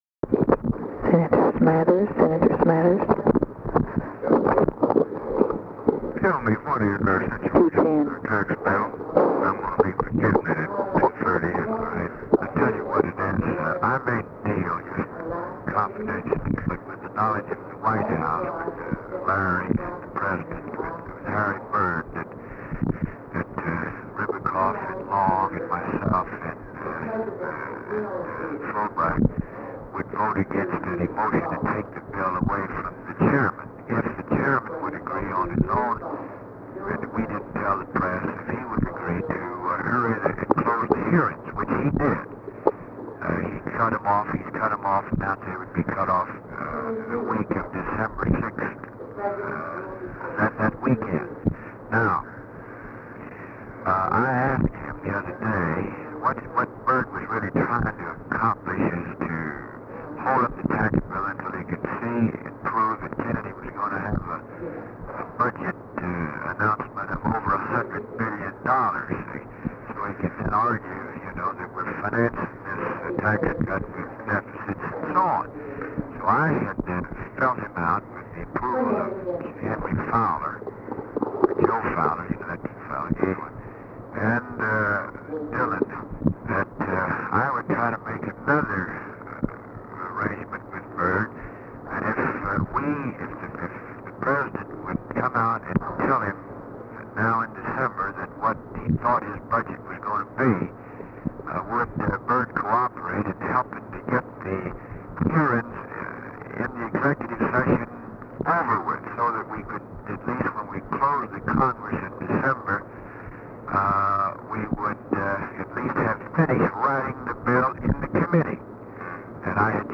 Conversation with GEORGE SMATHERS, November 23, 1963
Secret White House Tapes